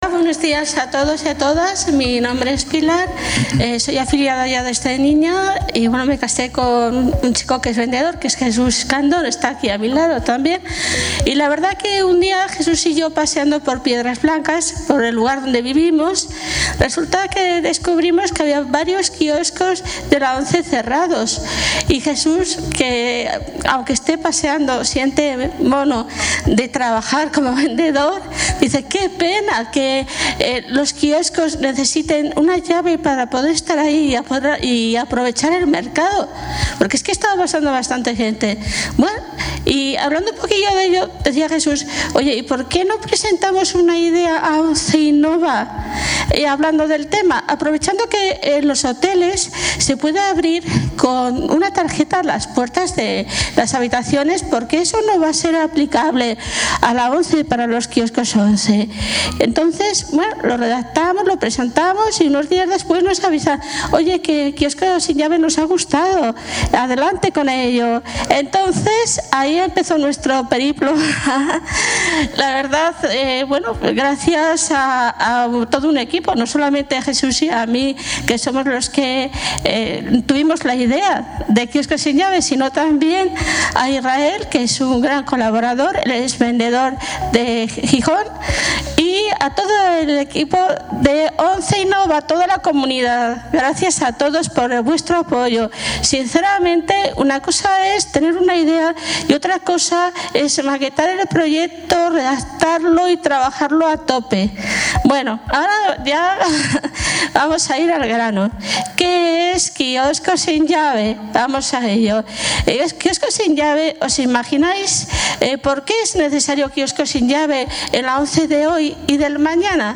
en la gala de entrega de premios.